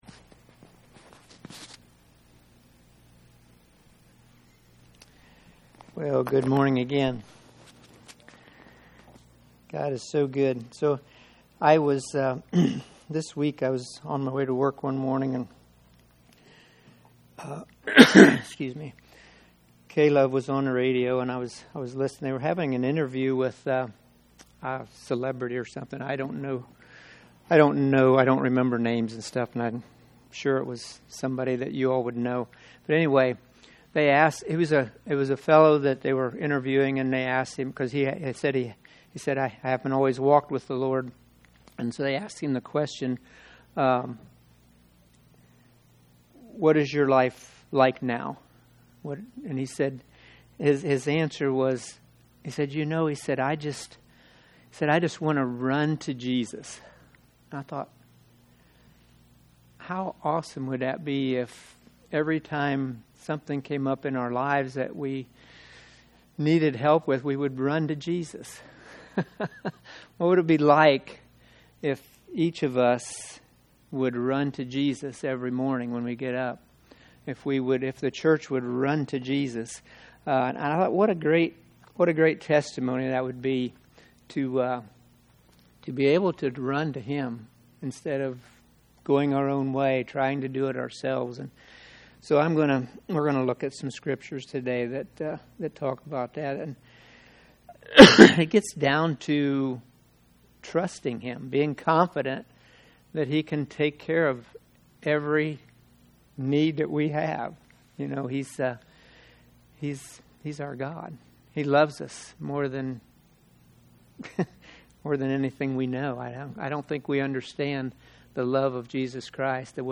2025 I Run to Jesus Preacher